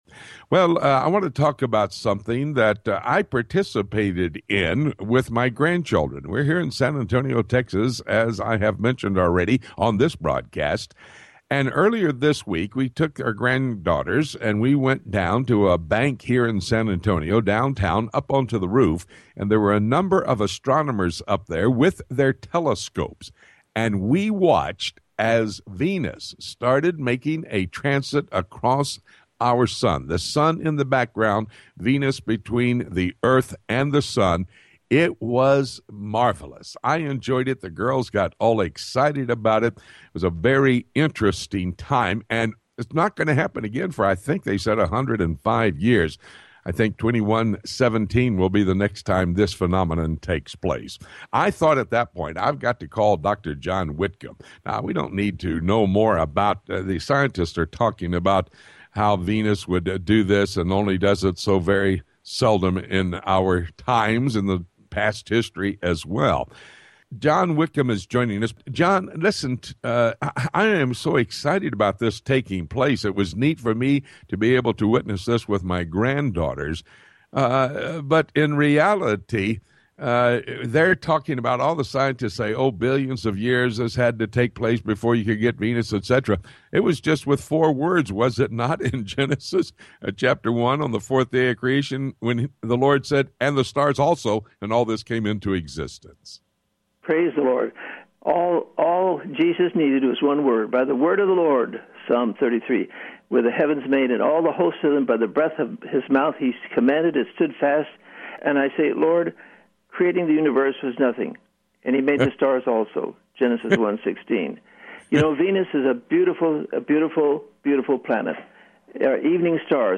Weekend Interviews – June 9, 2012